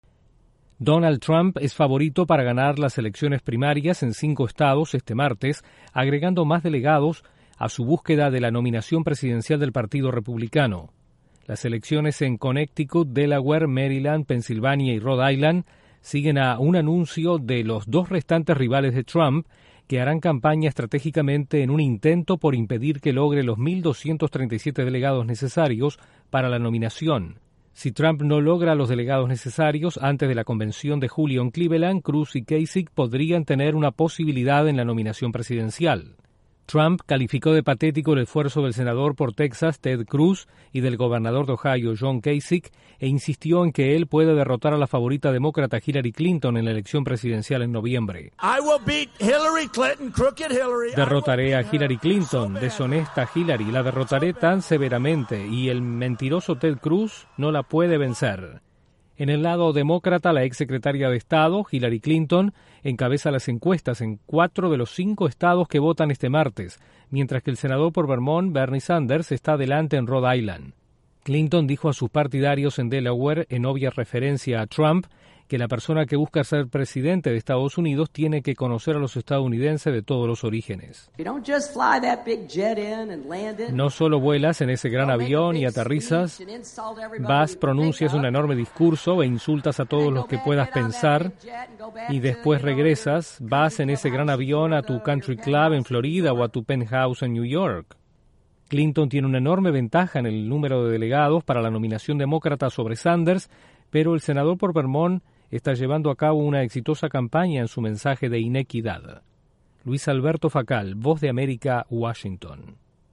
Trump y Clinton son favoritos para las primarias que se realizan en cinco estados este martes. Desde la Voz de América en Washington informa